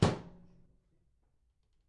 生活方式 " 微波炉门